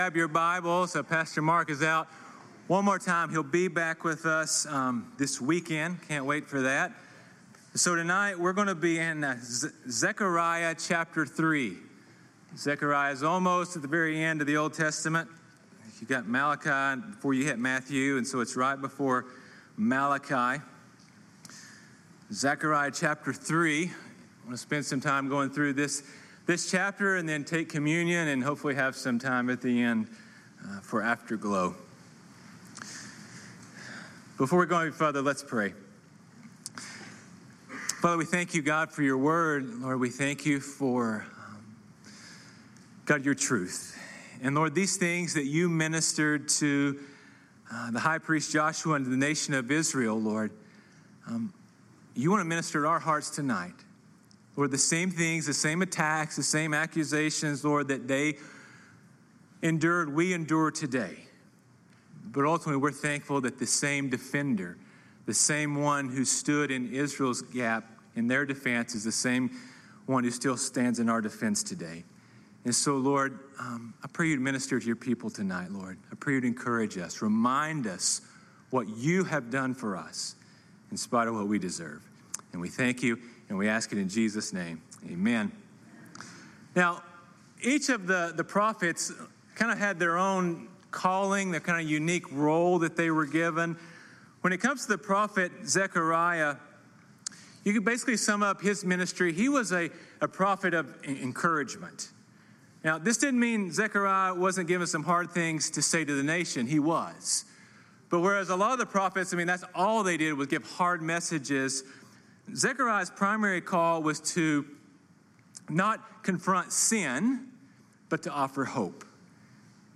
sermons Zechariah 3:1-10 | Accused But Not Condemned